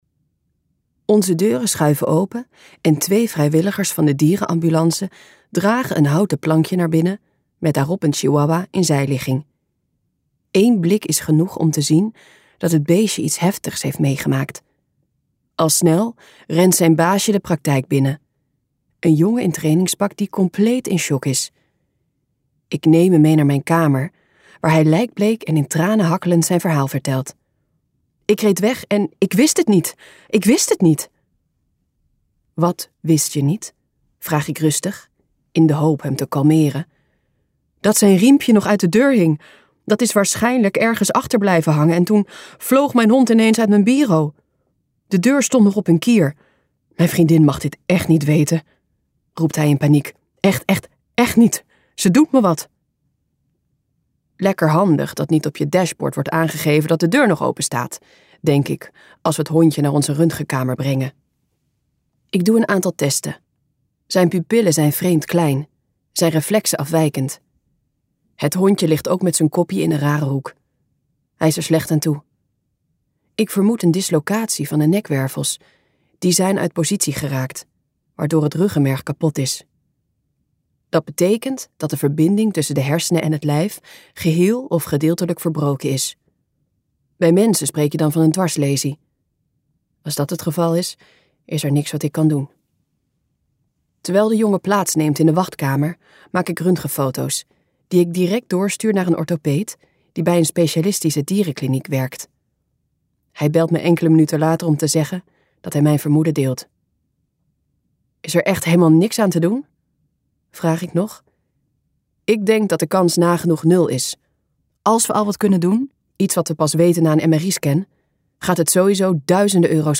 Ambo|Anthos uitgevers - Komt een mens bij de dierenarts luisterboek